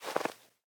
Minecraft Version Minecraft Version latest Latest Release | Latest Snapshot latest / assets / minecraft / sounds / block / powder_snow / step8.ogg Compare With Compare With Latest Release | Latest Snapshot
step8.ogg